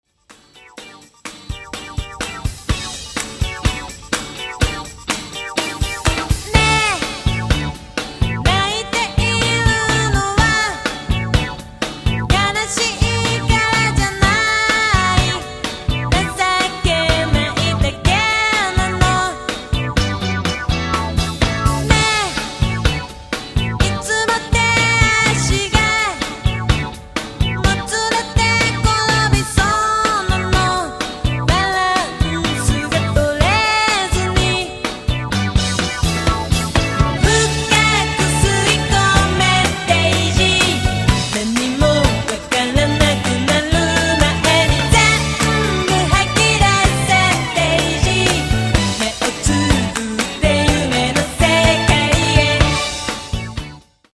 今作では生のドラムサウンドを取り入れ、よりグルーヴィーでパワフルなサウンドに。